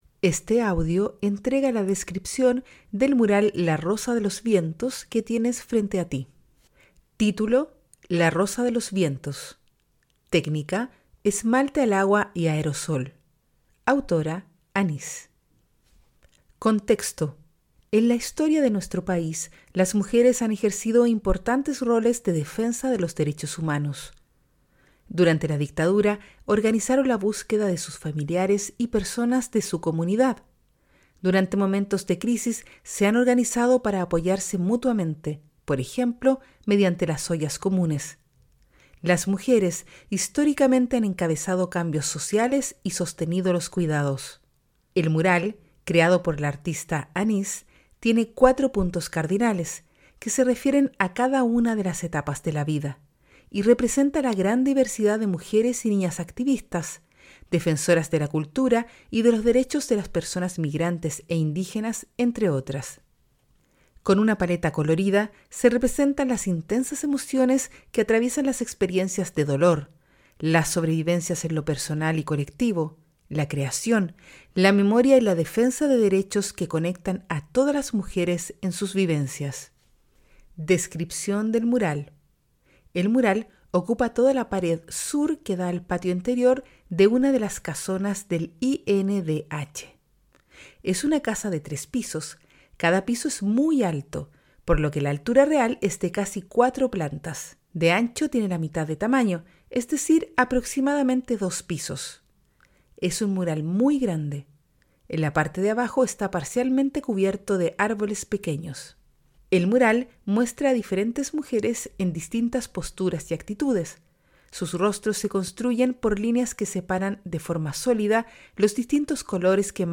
Audiodescripción
AUDIODESCRIPCION-MURAL.mp3